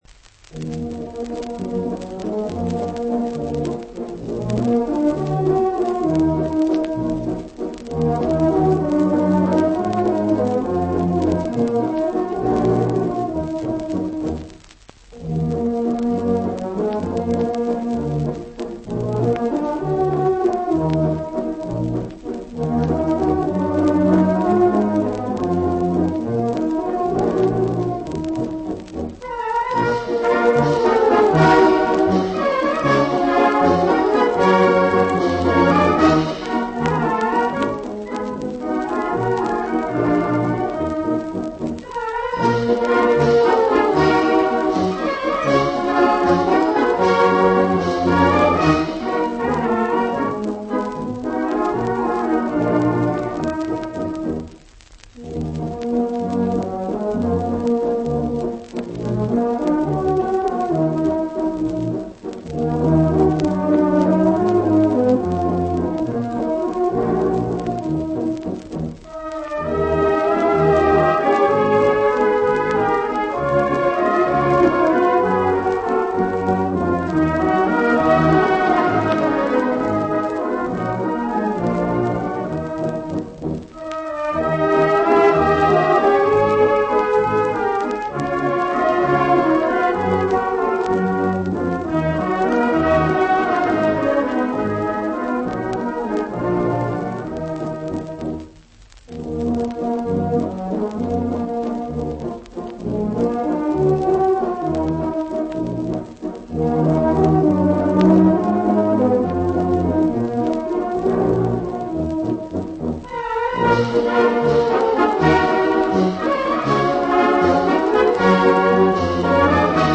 «Старинный» вальс «На сопках Маньчжурии» — вальс Ильи Алексеевича Шатрова…